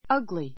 ugly A1 ʌ́ɡli ア グ り 形容詞 比較級 uglier ʌ́ɡliə r ア グ りア 最上級 ugliest ʌ́ɡliist ア グ りエ スト ❶ 醜 みにく い , 不格好な an ugly toad an ugly toad 醜いヒキガエル That building is old and ugly.